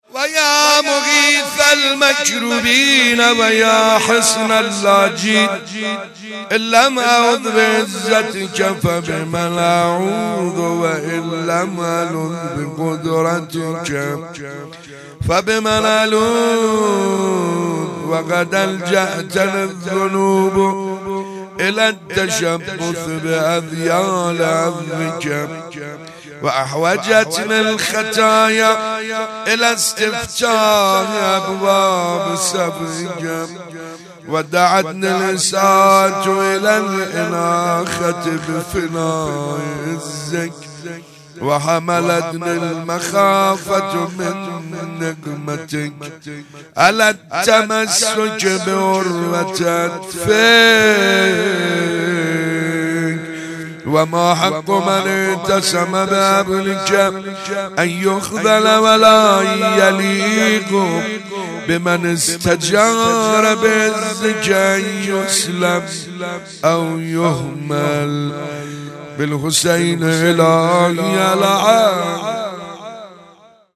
فاطمیه 95_روز پنجم_مناجات